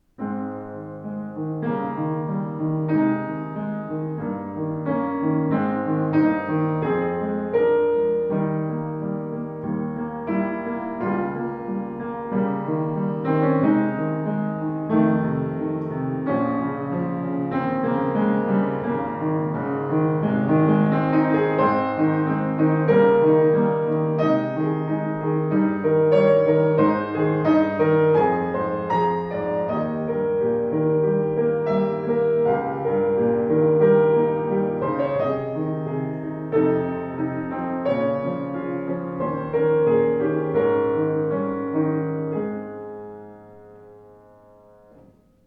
Voller, gestaltungsfähiger Klang, angenehm flüssige Spielart.